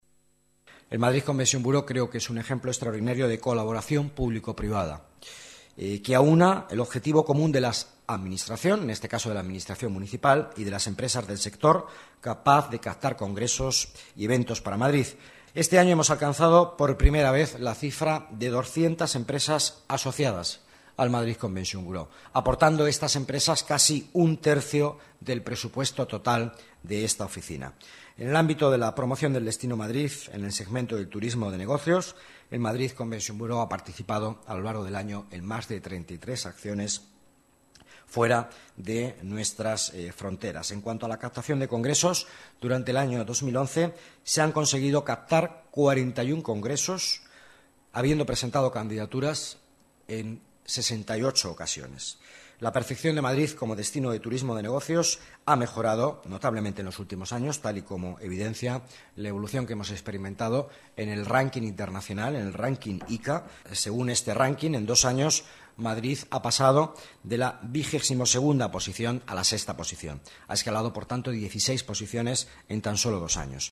Nueva ventana:Declaraciones del vicealcalde, Miguel Ángel Villanueva